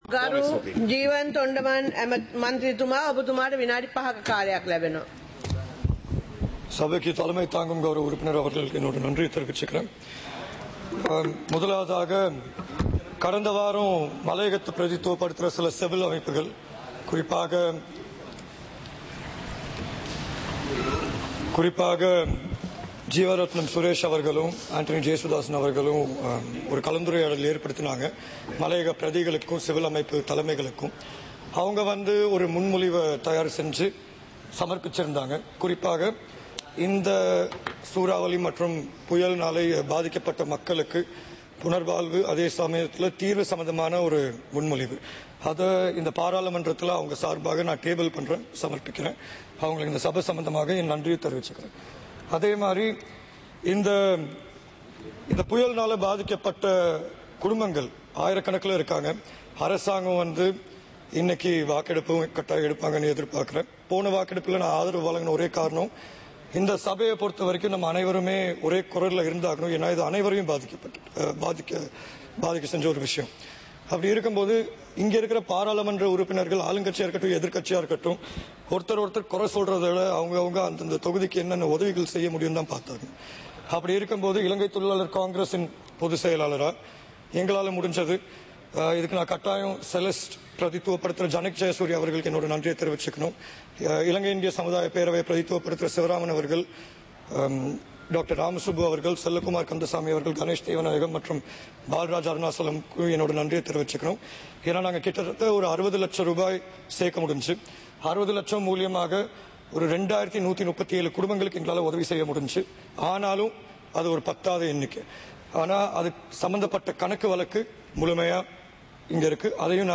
සභාවේ වැඩ කටයුතු (2025-12-19)